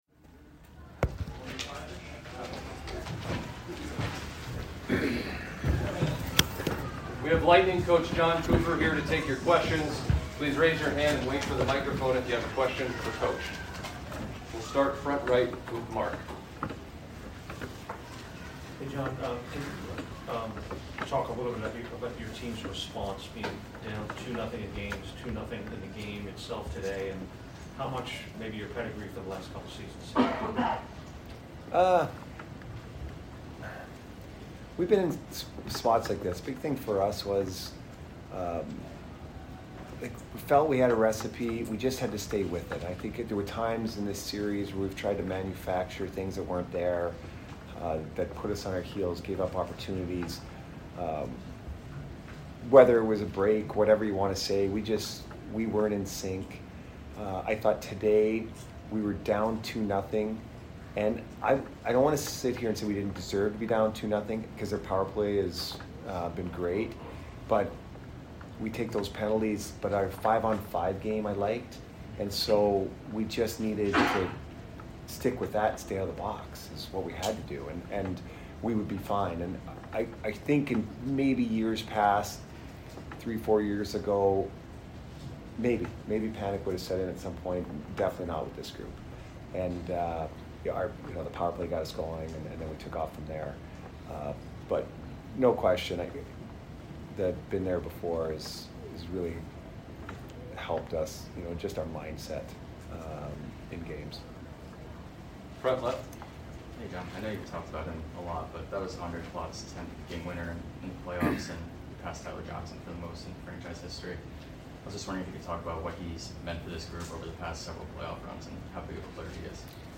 Head Coach Jon Cooper Post Game Vs NYR 6 - 5-2022